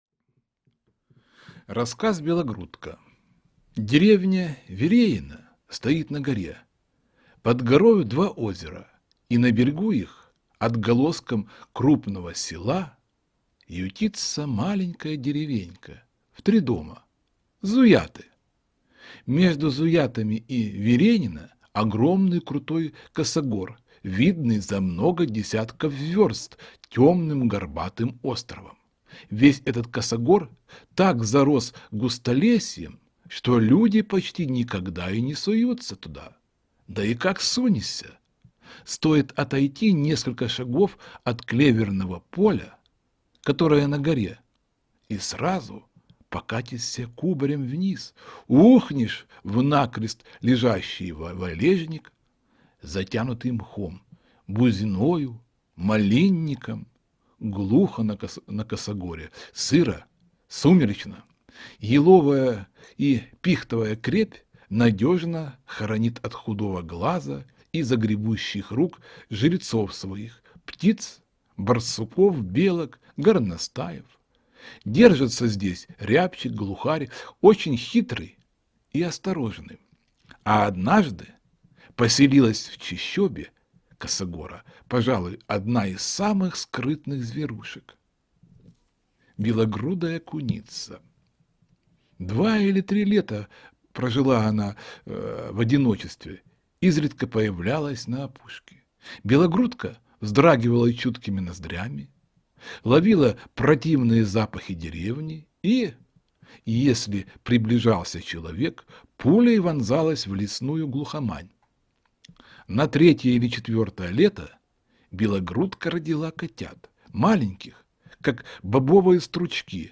Белогрудка - аудио рассказ Астафьева В.П. Рассказ о том, как бережно надо относиться к животным в природе.